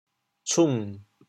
潮阳拼音“cung3”的详细信息
国际音标 [ts]
同音汉字 吋 寸